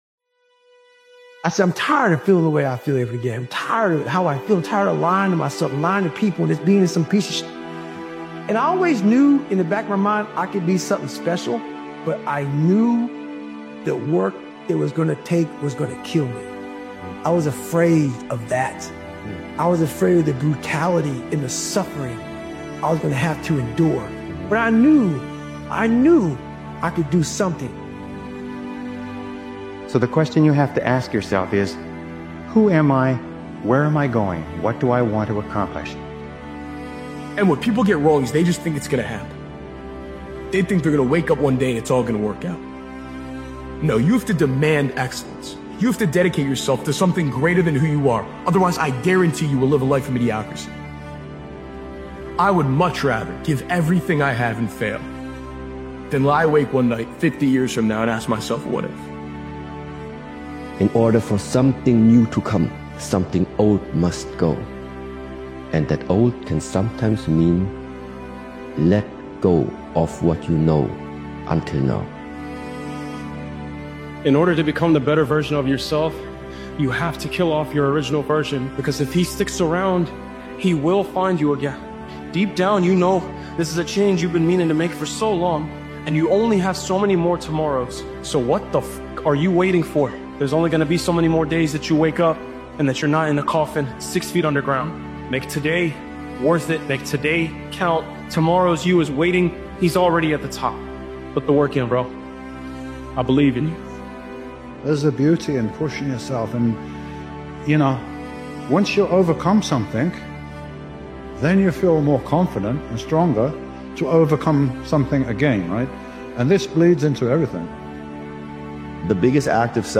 powerful motivational speech by Daily Motivations is about cutting off the version of you that’s holding you back and rebuilding from the ground up. It takes discipline, consistency, and mental toughness to break old habits, eliminate excuses, and create a stronger identity.